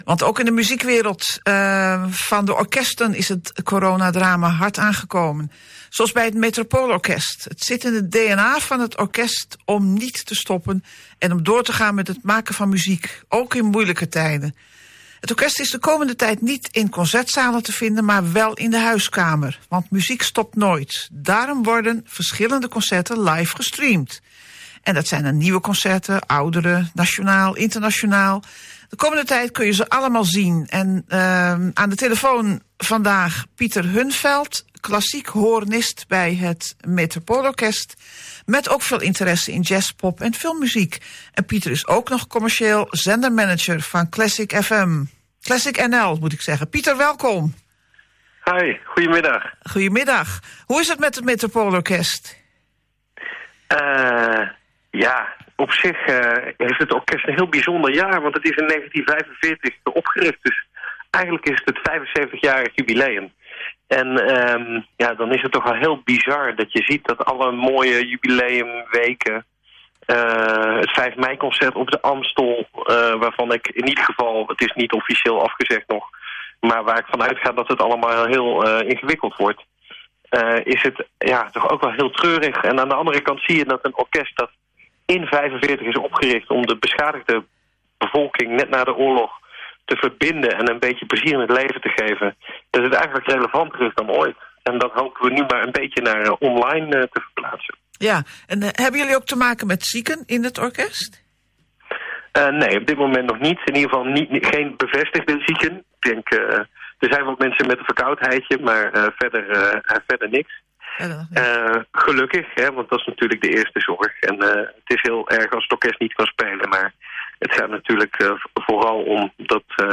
Aan de telefoon vandaag